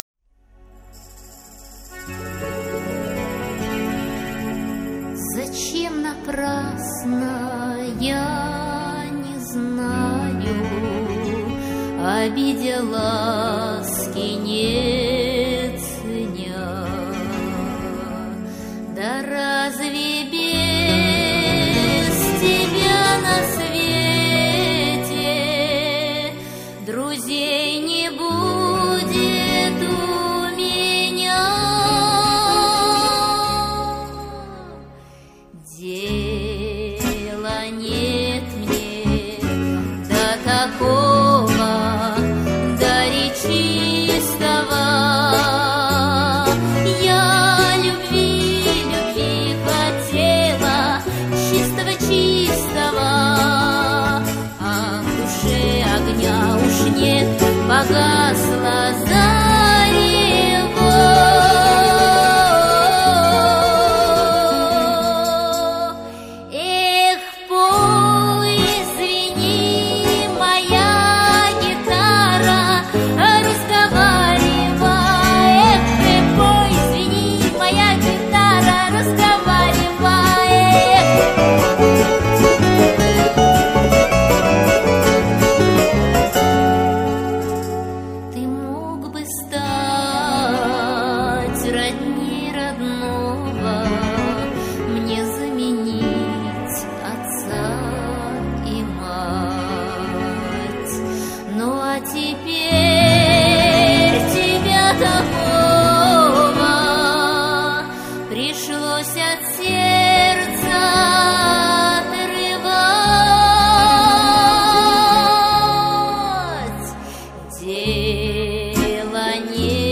Жанр: Поп-фолк